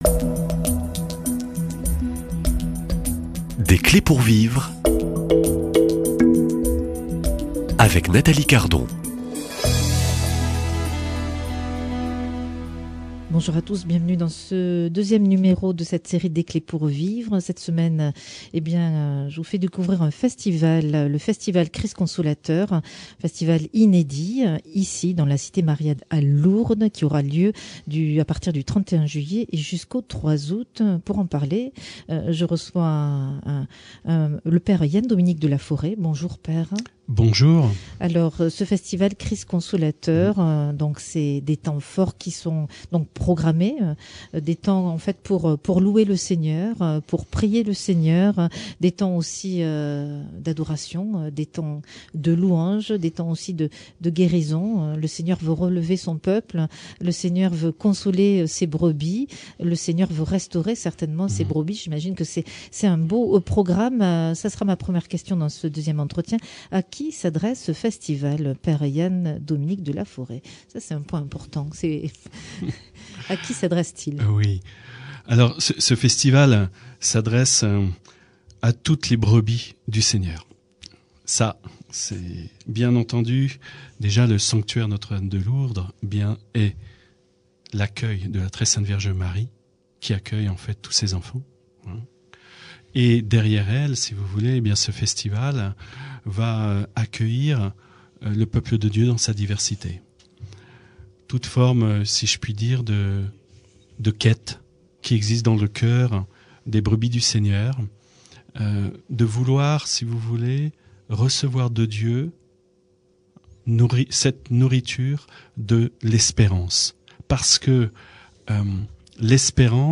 Dans ce deuxième entretien, Christine Boutin revient sur les heures de solitude, de combat, mais aussi de fidélité.